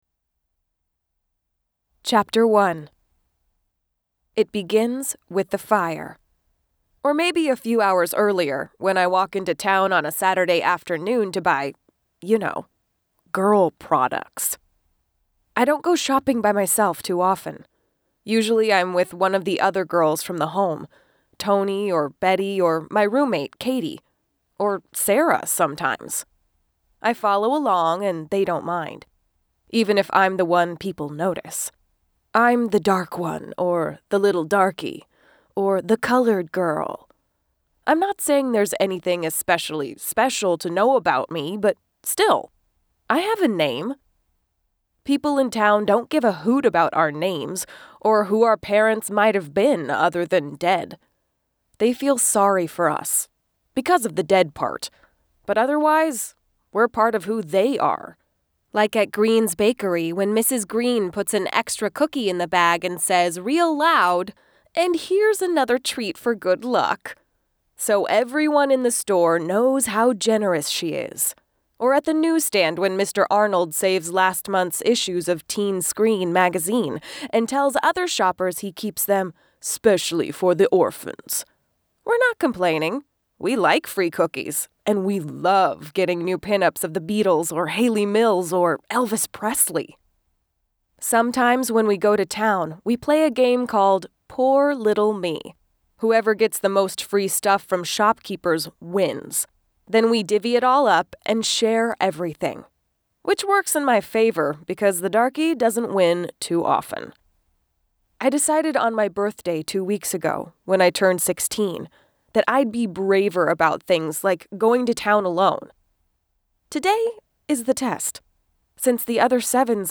Download the A Big Dose of Lucky Audio Sample.